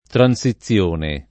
vai all'elenco alfabetico delle voci ingrandisci il carattere 100% rimpicciolisci il carattere stampa invia tramite posta elettronica codividi su Facebook transizione [ tran S i ZZL1 ne ] s. f. («passaggio») — cfr. transazione